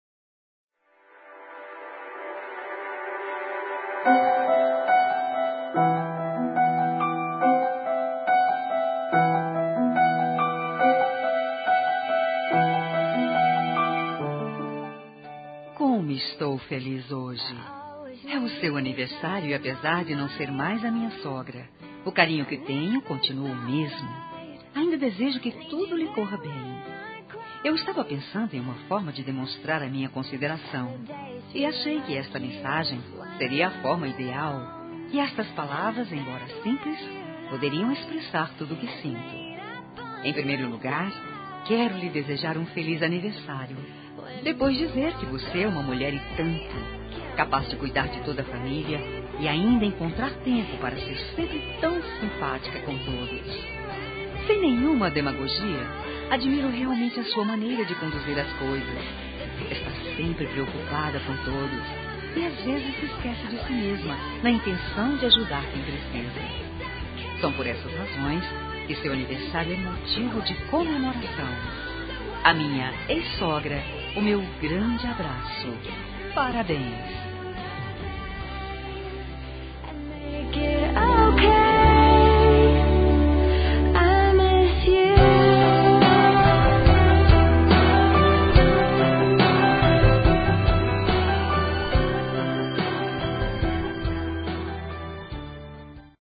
Telemensagem Aniversário de Sogra – Voz Feminina – Cód: 202168 – Ex-sogra